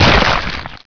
sound / monsters / plagueElf / gib2.wav